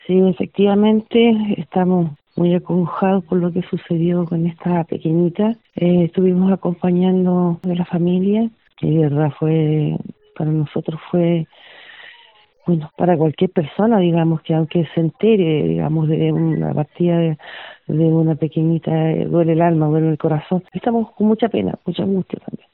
Así lo detalló a La Radio la alcaldesa Jacqueline Romero, quien ha acompañado a la familia y vecinos afectados por la tragedia.